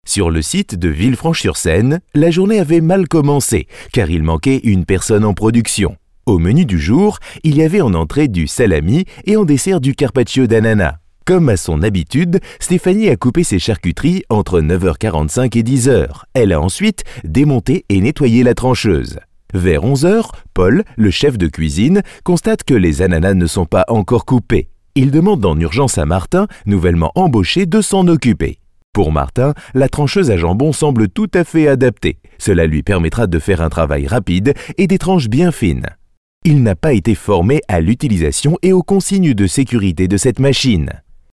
Sprechprobe: eLearning (Muttersprache):
French native (no accent) middle age male voice-talent since 1988, i have my own recording facilities and deliver in 2 to 6 hours ready to use wavs/mp3 files, paypal accepted, my voice is clear sounding serious but friendly at the same time !